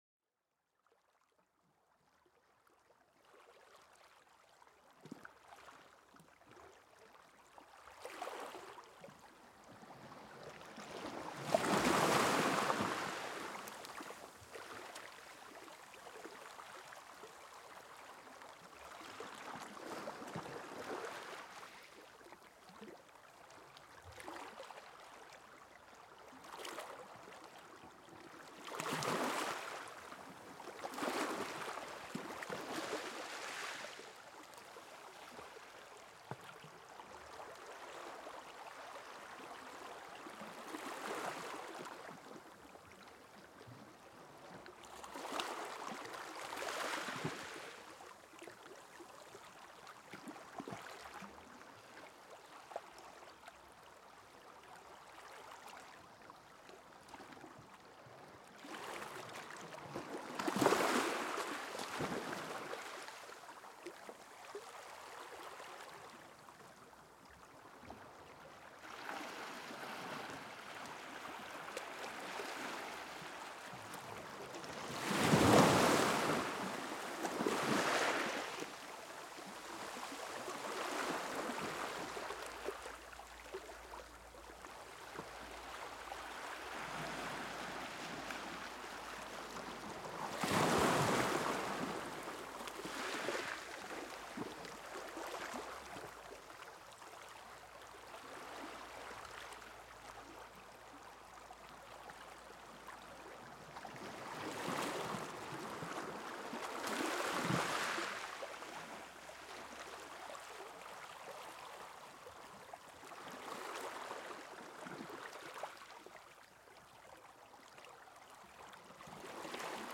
Les vagues apaisantes de la mer pour une relaxation profonde